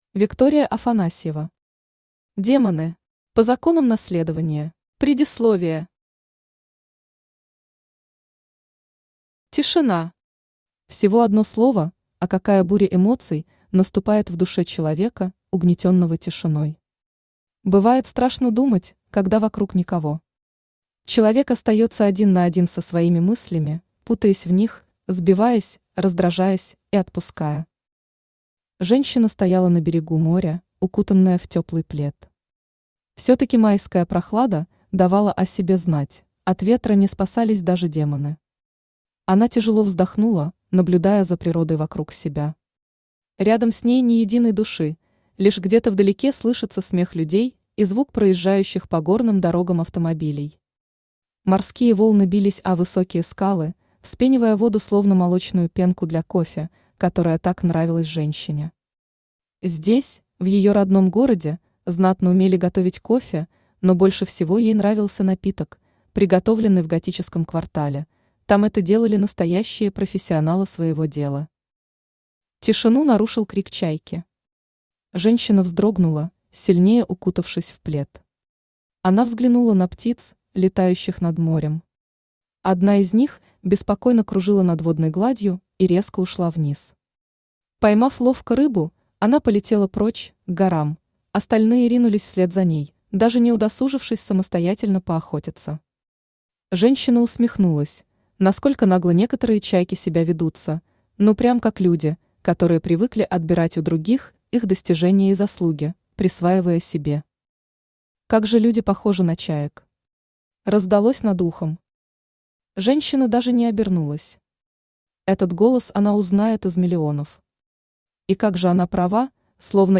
Аудиокнига Демоны. По законам наследования | Библиотека аудиокниг
По законам наследования Автор Виктория Афанасьева Читает аудиокнигу Авточтец ЛитРес.